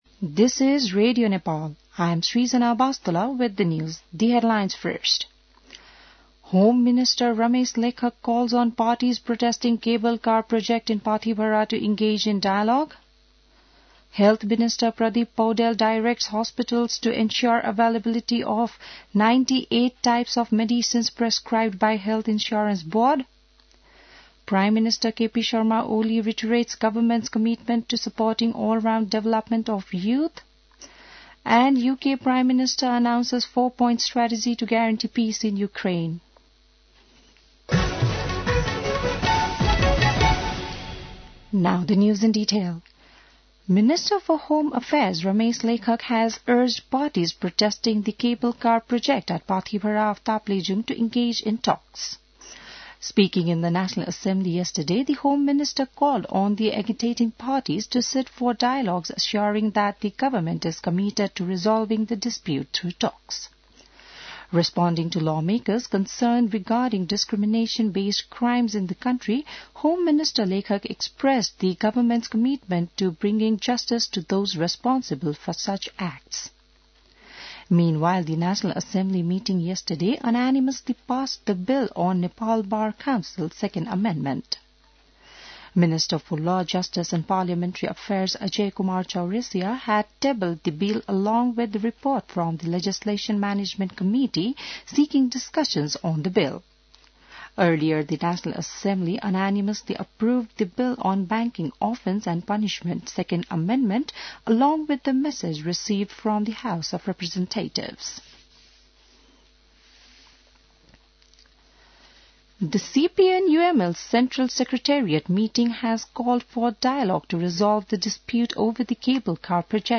बिहान ८ बजेको अङ्ग्रेजी समाचार : २० फागुन , २०८१